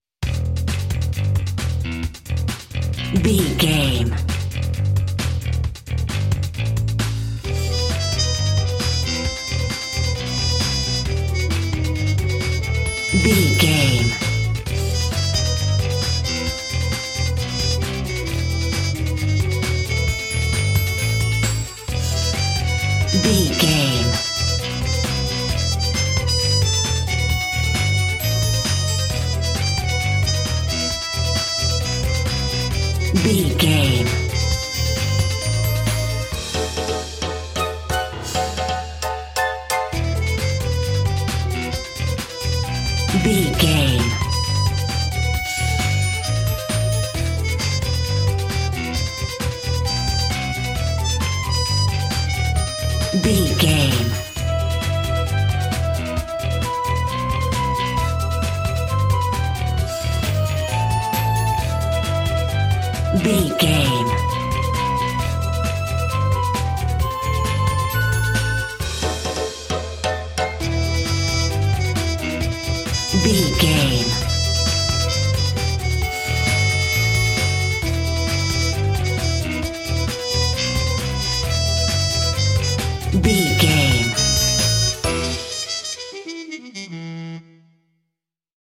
Fast paced
Aeolian/Minor
Fast
aggressive
driving
dark
dramatic
groovy
frantic
suspense
horns
violin
bass guitar
drums
trumpet
brass
percussion
70s